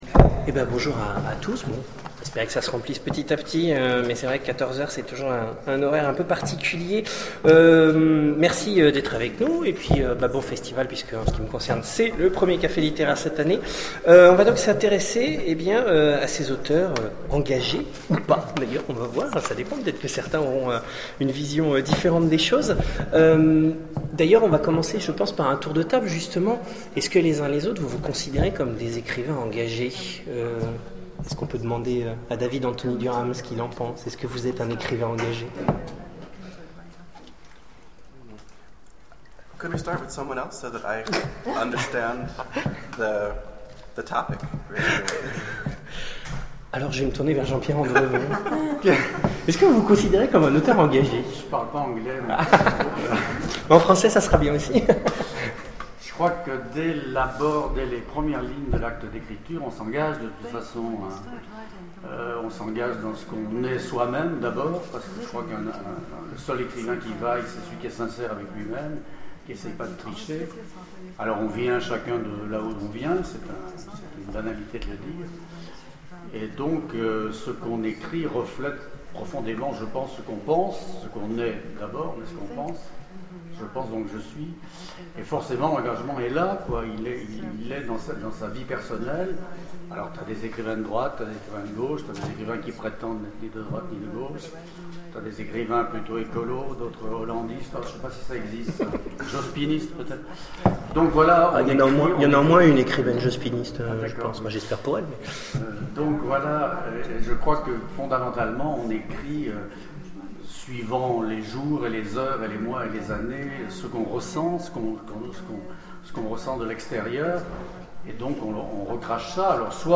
Imaginales 2012 : Conférence Auteurs et récits engagés...